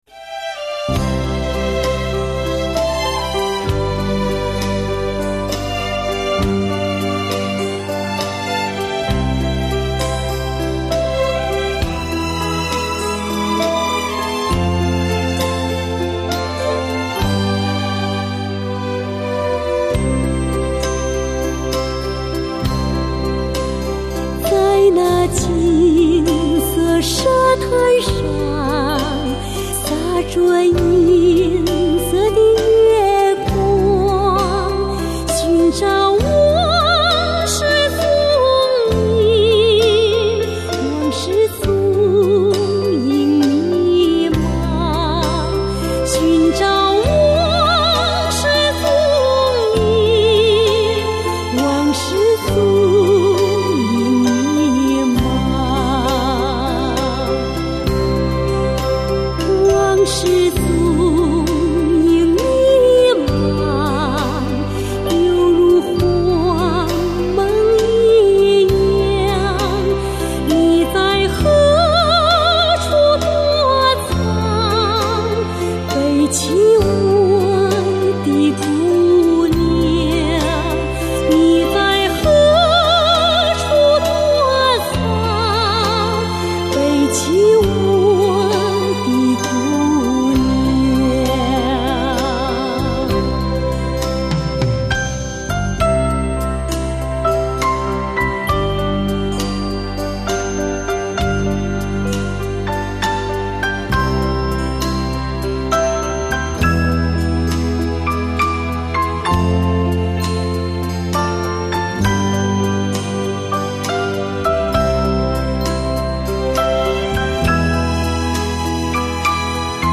音乐风格: 流行
音色甜美，融通俗、民族和美声于一体。